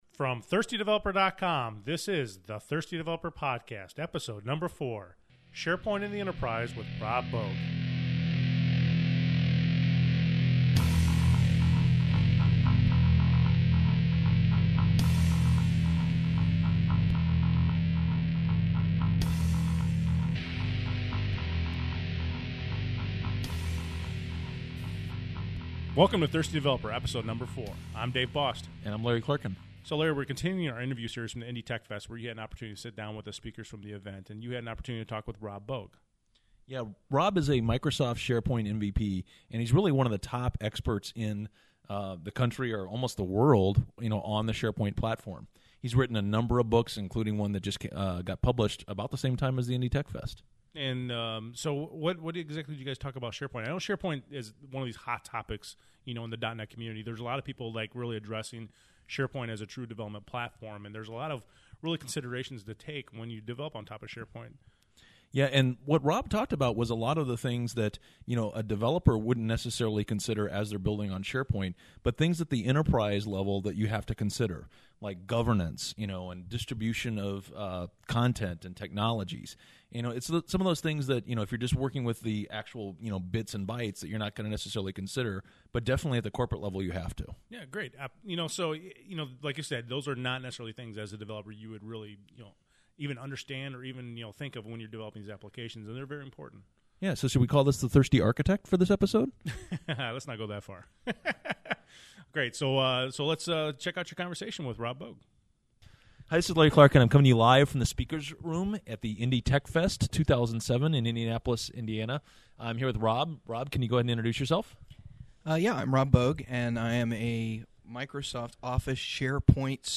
This is the third in our series of interviews from the IndyTechfest in Indianapolis, IN.